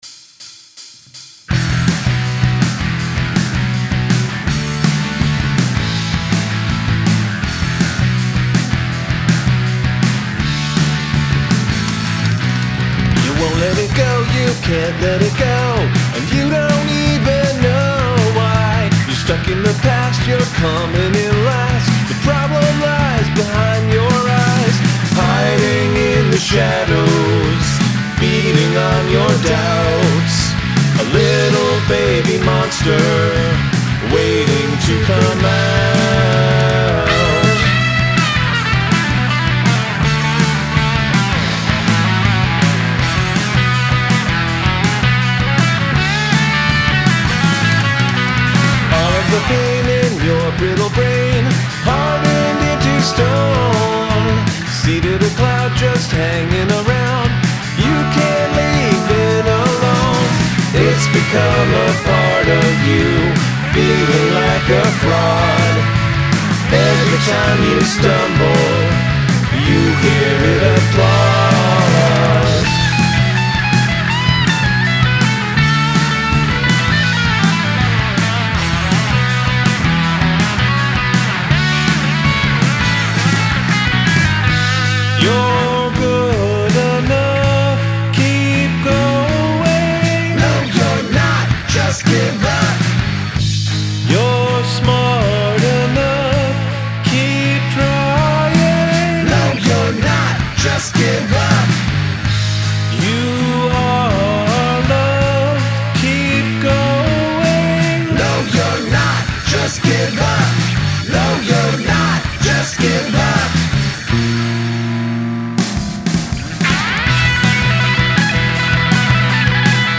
The harmony vocals sprinkled in are nice.
Strength: performance, lead guitar
This is a nice poppy guitarry feel-good tune.
Gosh this has a lot of guitar solos.